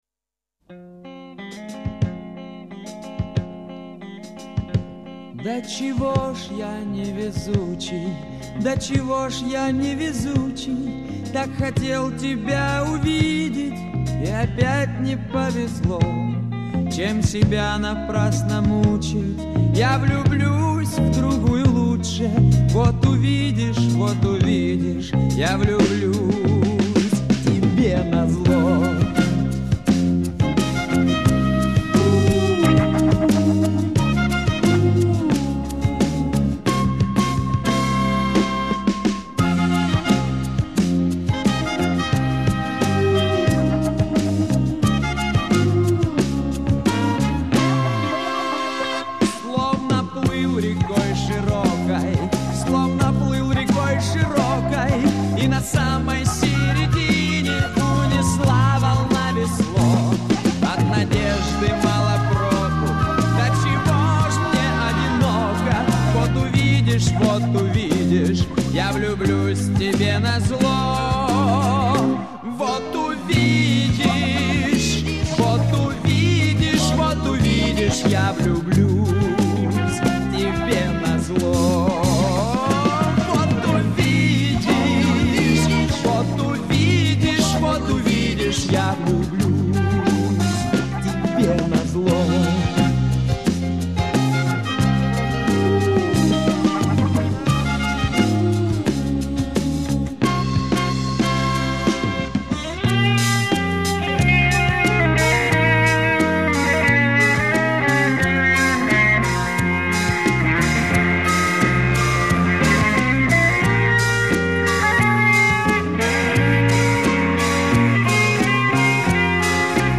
на гитаре